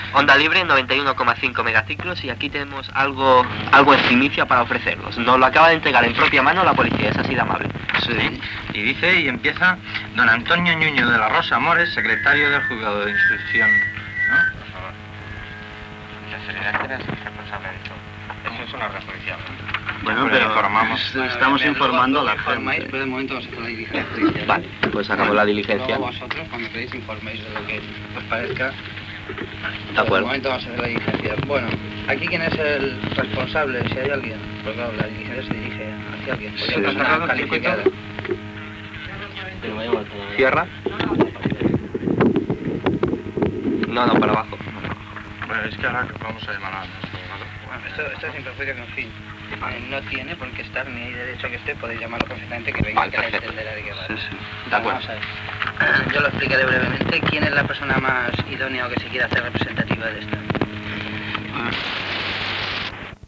Clausura de l'emissora en directe per la policia.
Informatiu